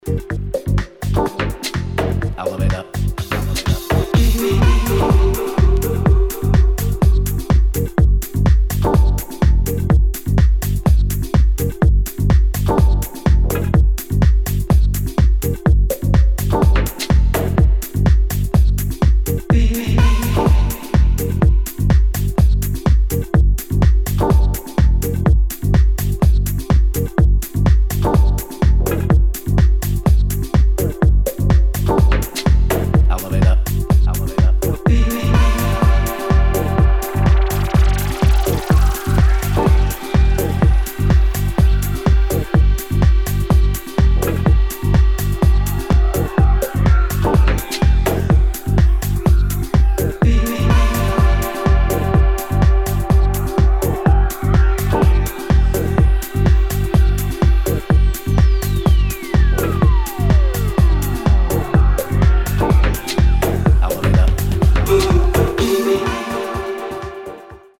[ HOUSE / TECH HOUSE ]